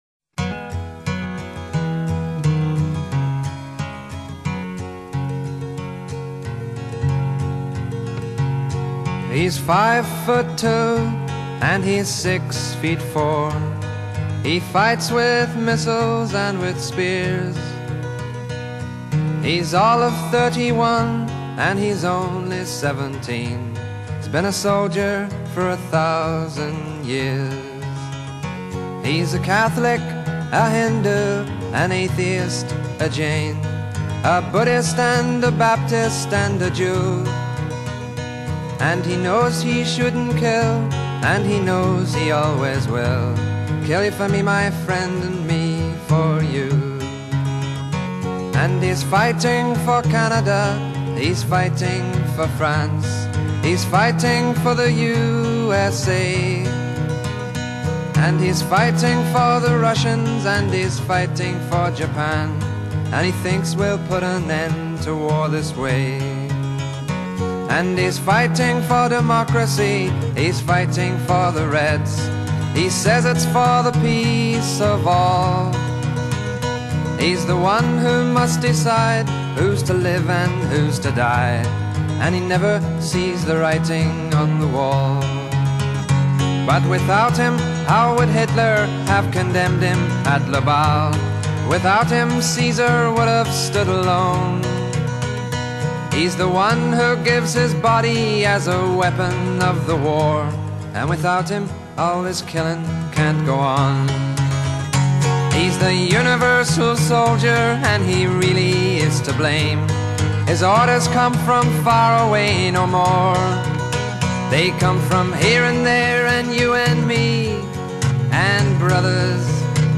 类型：Folk